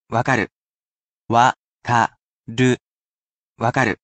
wakaru